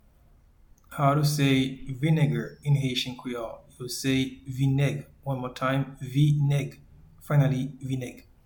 Pronunciation and Transcript:
Vinegar-in-Haitian-Creole-Vineg.mp3